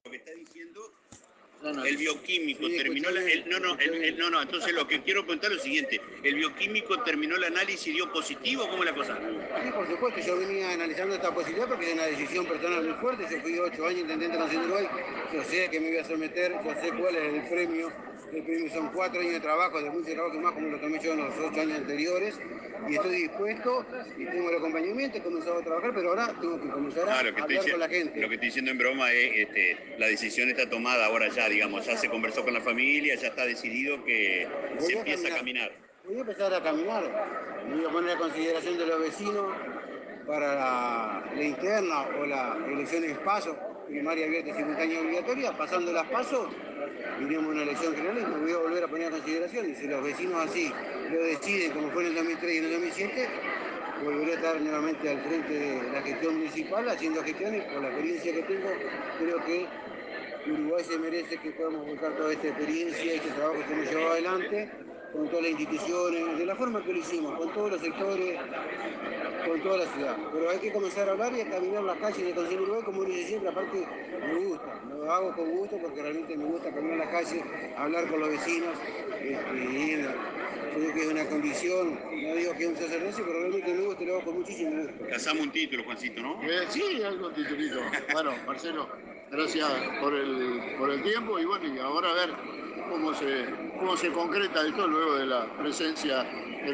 En los micrófonos de la radio pública Marcelo Bisogni dijo: "Venía pensando esta posibilidad porque es una decisión personal muy fuerte.